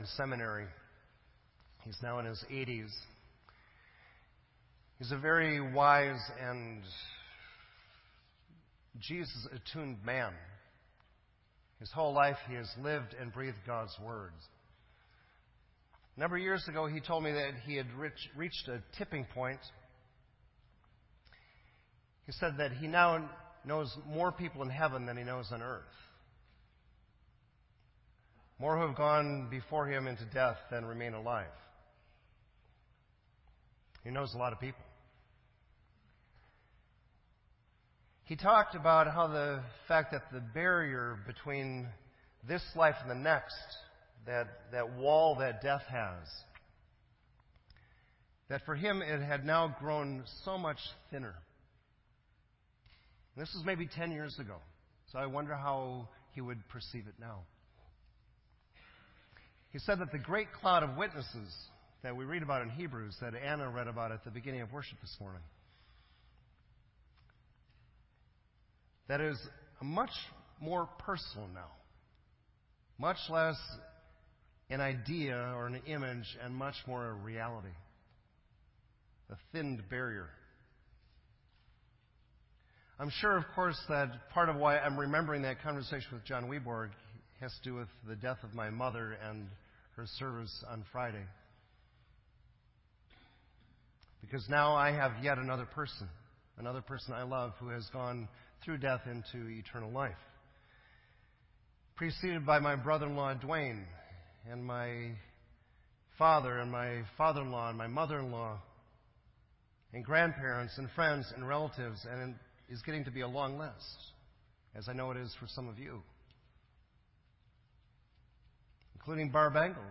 This entry was posted in Sermon Audio on November 5